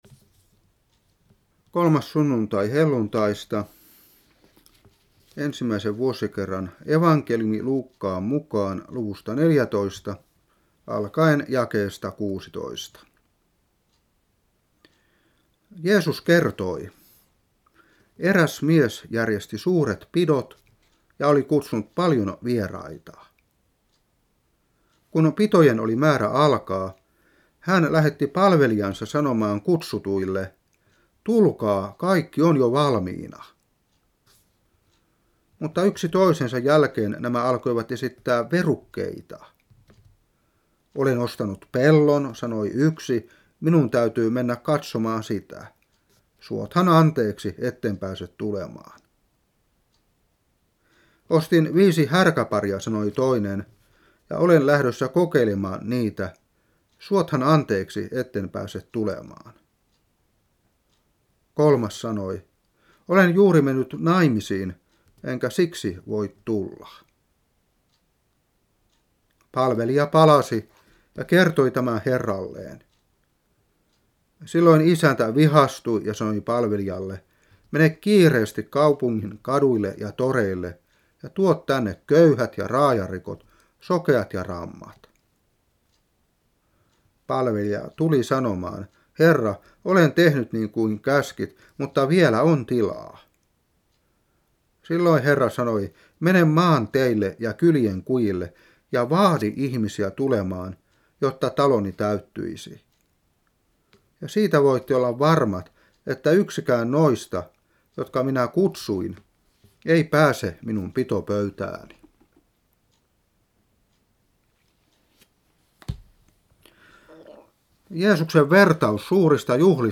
Saarna 1996-6. Luuk.14:16-24.